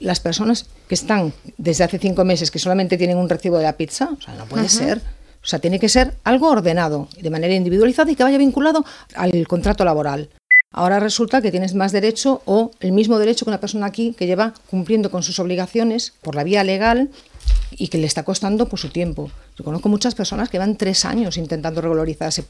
En una entrevista a Ràdio Calella TV, Coronil ha criticat que la mesura s’hagi aprovat “per decret” i sense debat polític, i alerta de l’impacte que pot tenir sobre els serveis municipals.